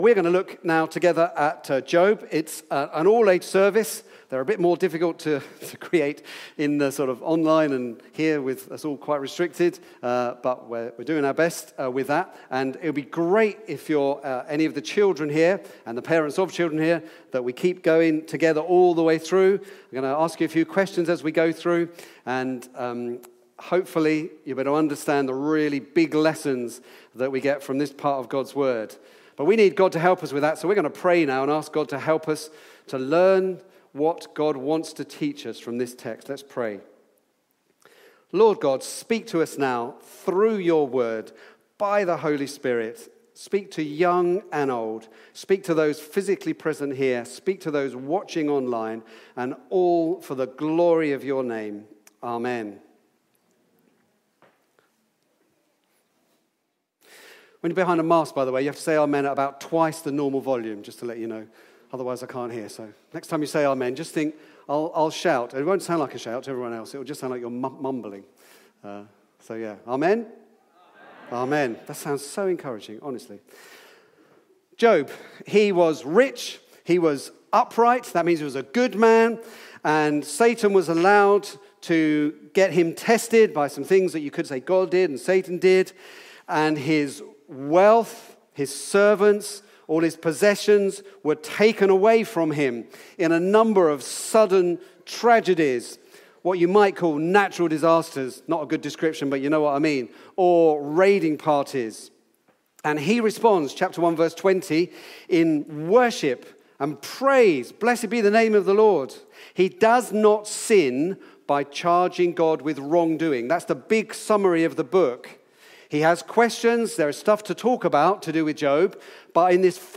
Back to Sermons Darkness